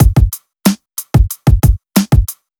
FK092BEAT3-L.wav